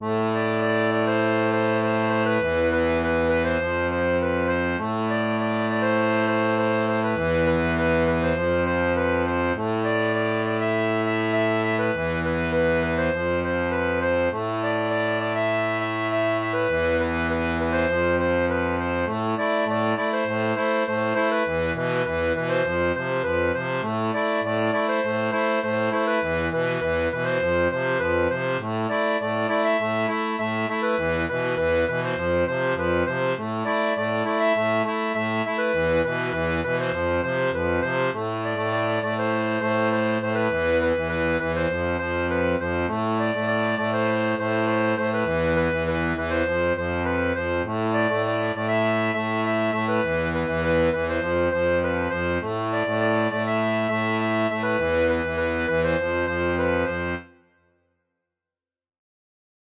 • La tablature transposée en A pour diato 2 rangs
Electro